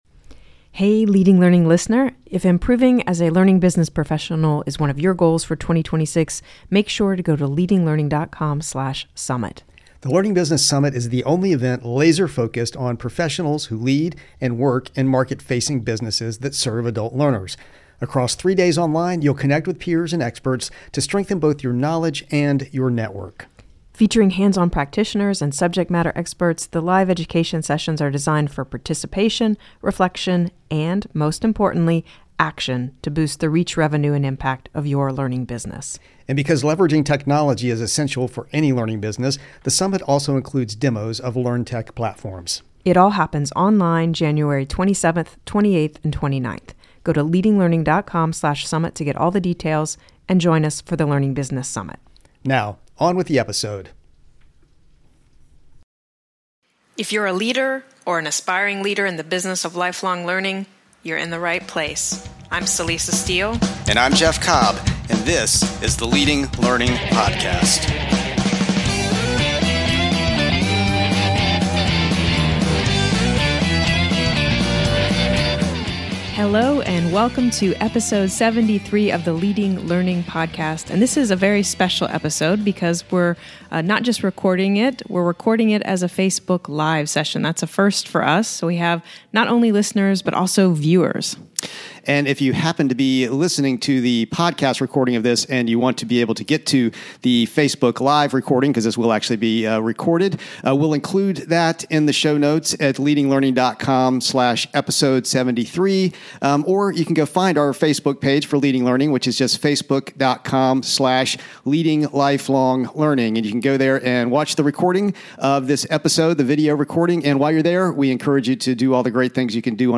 In keeping up with our tradition of doing an After Action Review whenever we have a major event, this time around, we are going to be talking about Learning • Technology • Design™ (LTD) 2017, the virtual conference we hosted last week. And, to continue the spirit of connecting virtually, this special episode was also recorded as a Facebook Live session on our Facebook page.